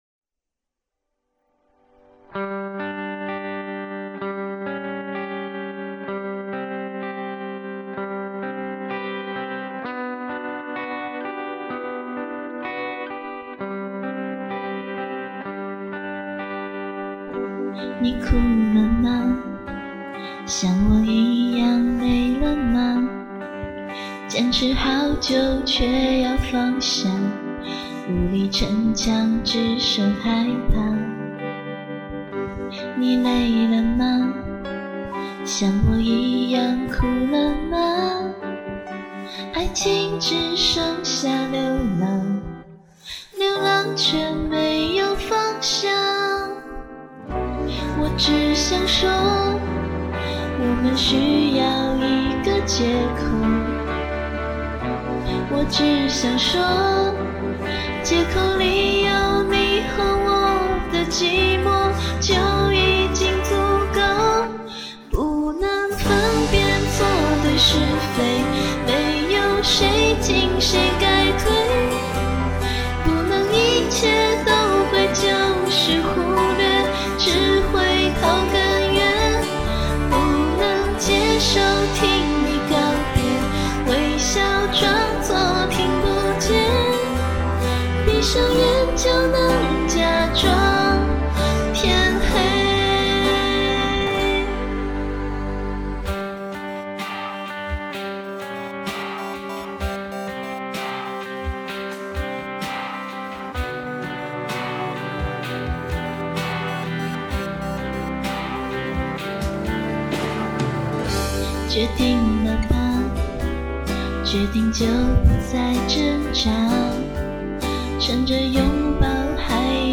曲风：抒情